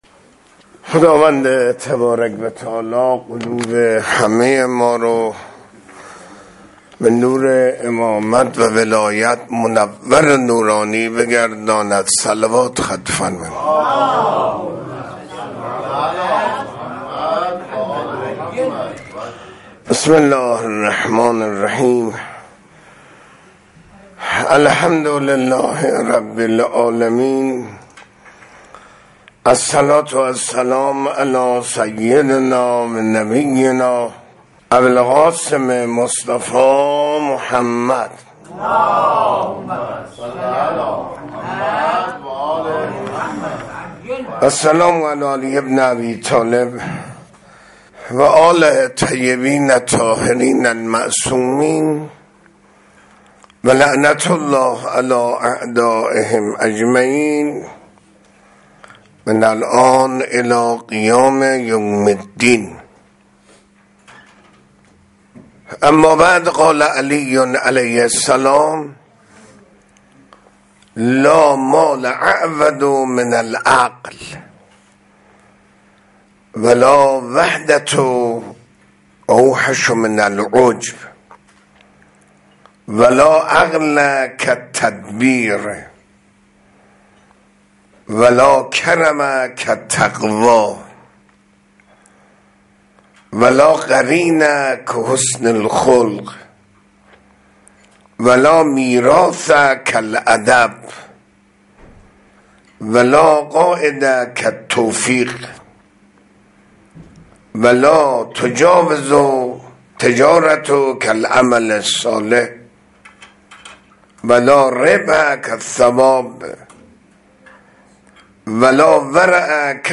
منبر 3 بهمن 1404، مسجد جوادالائمه (ع)